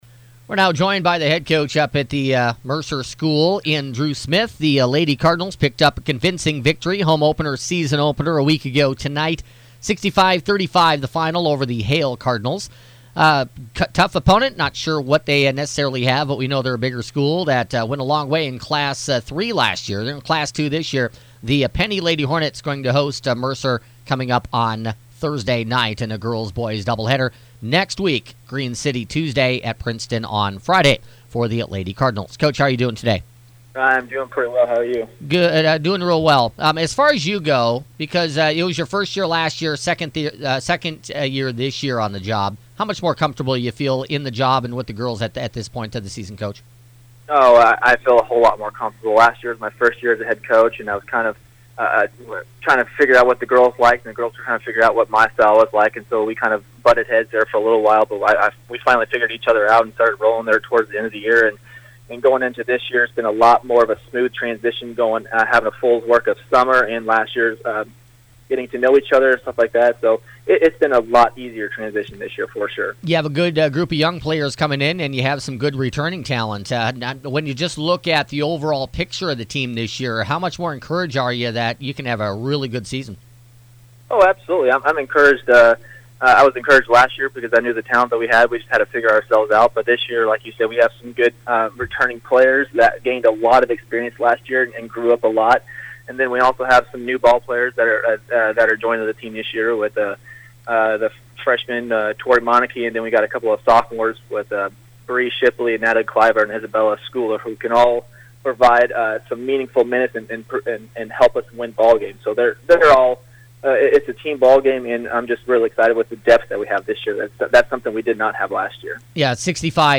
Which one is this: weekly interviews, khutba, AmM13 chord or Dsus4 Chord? weekly interviews